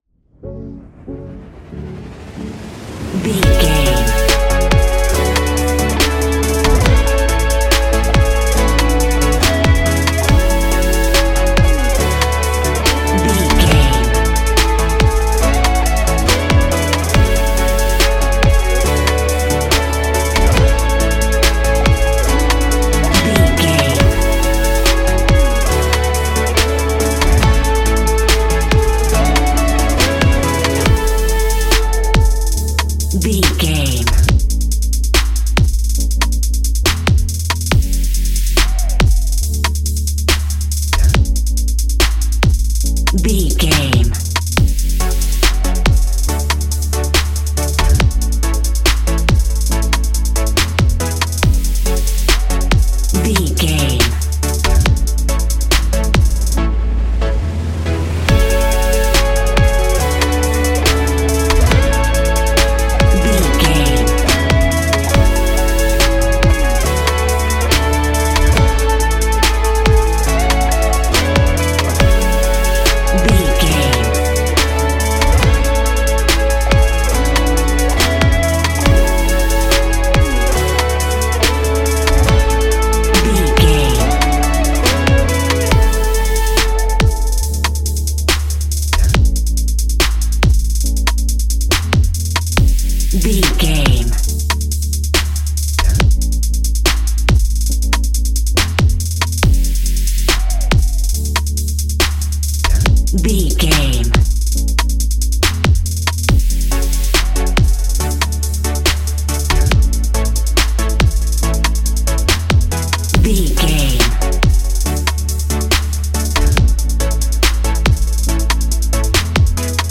Ionian/Major
D♭
ambient
new age
downtempo
synth
pads